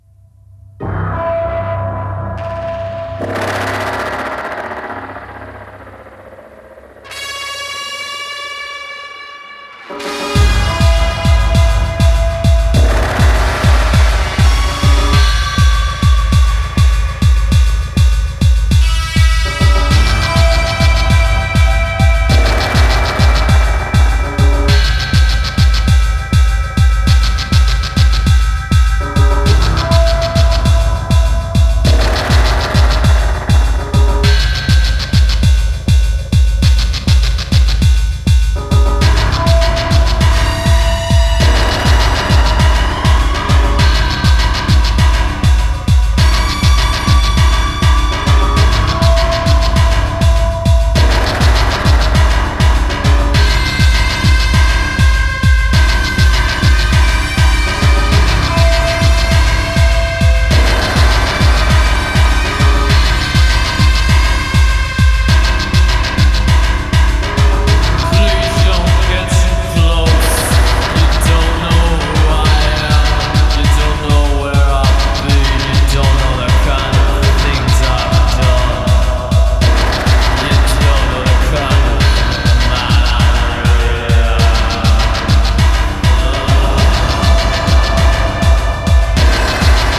Punk rooted Noise!
Electro Electronix Wave